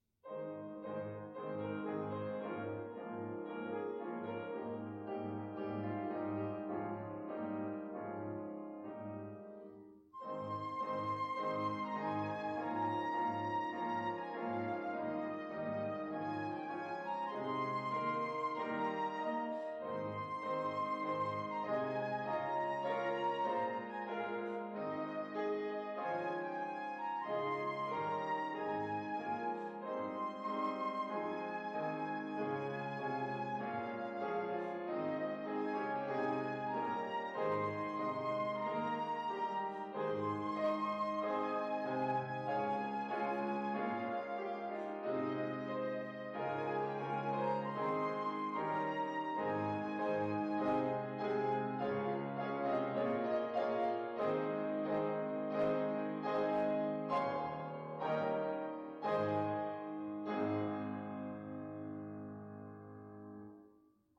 By Organist/Pianist
Flutist